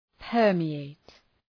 {‘pɜ:rmı,eıt}